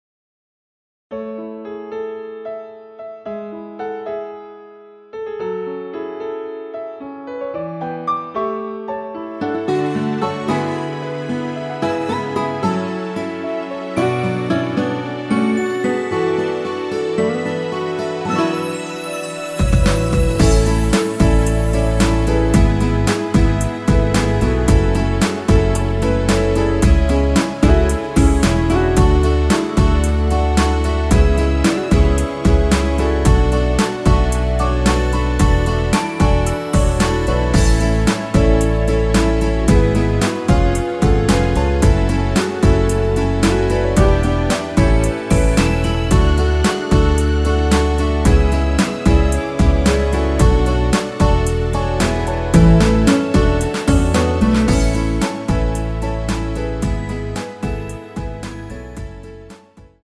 남자키에 멜로디 포함된MR 입니다.(미리듣기 참조)
대부분의 남성분이 부르실수 있는 키로 제작 하였습니다.
앨범 | O.S.T
앞부분30초, 뒷부분30초씩 편집해서 올려 드리고 있습니다.
중간에 음이 끈어지고 다시 나오는 이유는